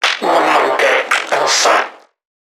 NPC_Creatures_Vocalisations_Infected [74].wav